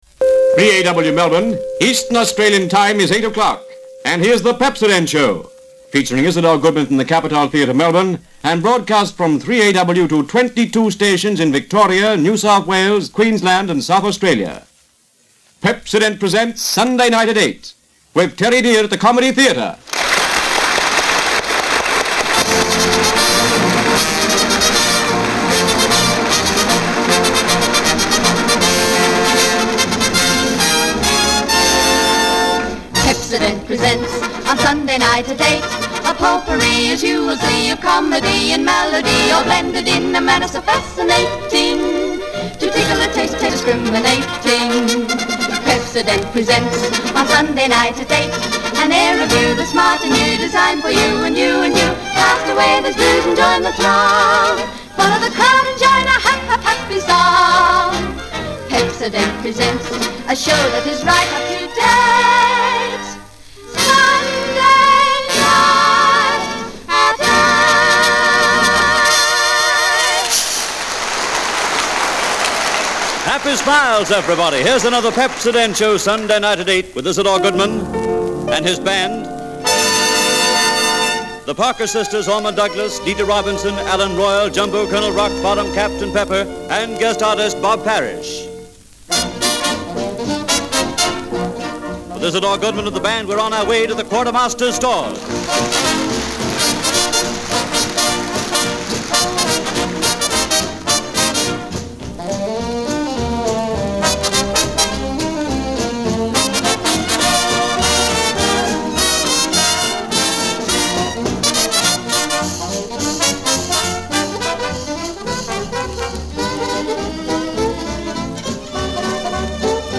Featuring Isador Goodman from the Capitol Theatre, Melbourne and broadcast from [1280kHz] 3AW Melbourne to 22 stations in Victoria, New South Wales, Queensland and South Australia.